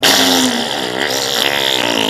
Sound Effects
Gross Wet Fart